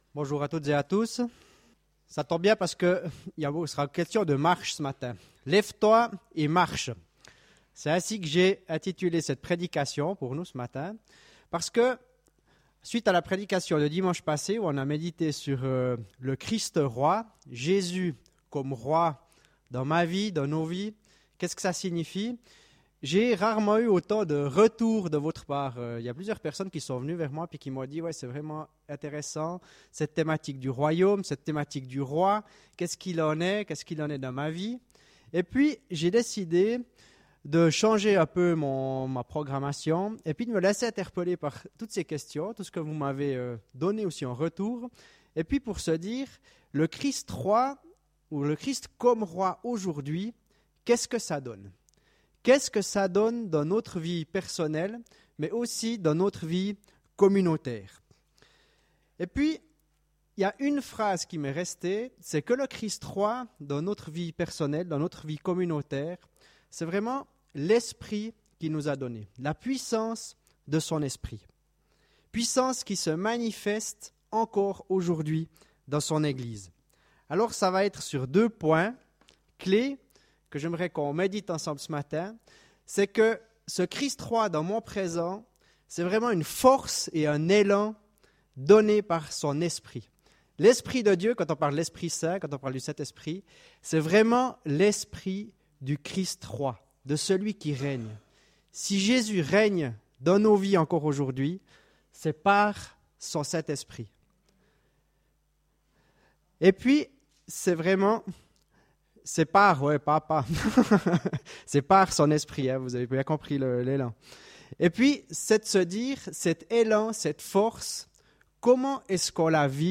Culte du 31 janvier 2016